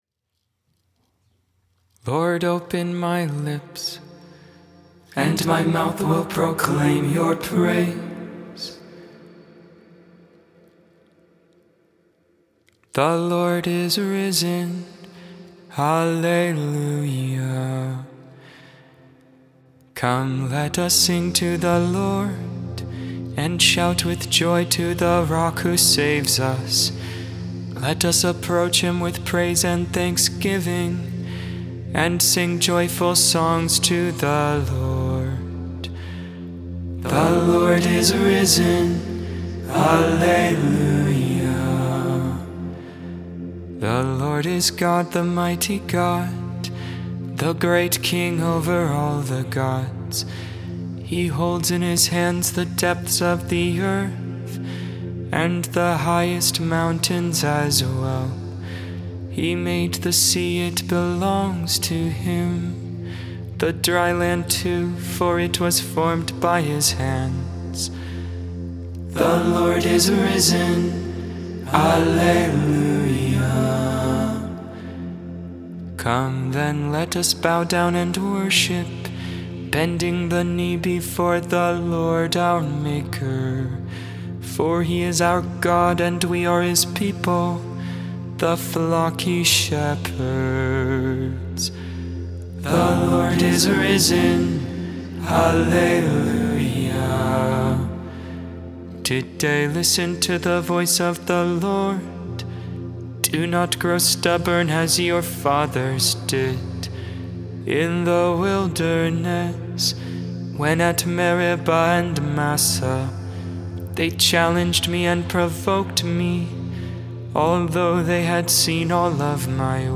Christ is risen! Lauds, Morning Prayer for Easter Sunday, April 17th, 2022.